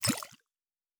Potion and Alchemy 03.wav